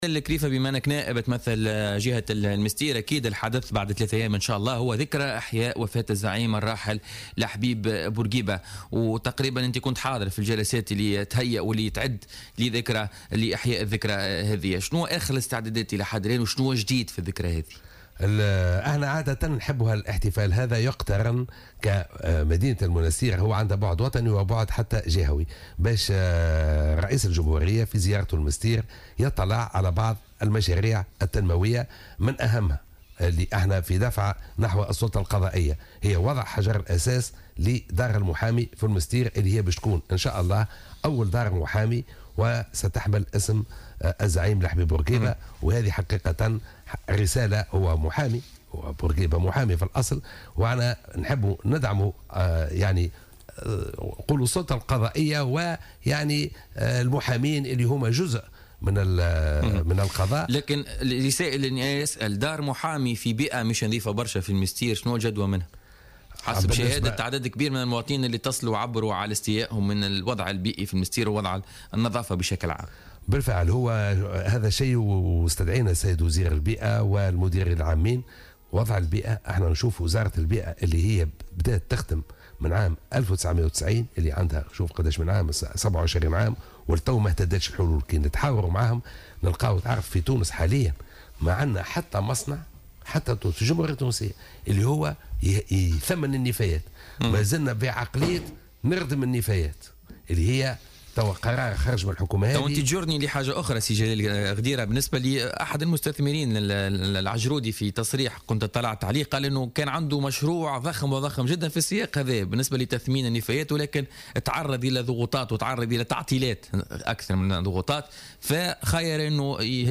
Le député du parti Nidaa Tounes, Mohamed Jalel Ghedira a affirmé que deux projets seront dévoilés cette semaine au gouvernorat de Monastir, à l'occasion de la commémoration du décès du leader Habib Bourguiba.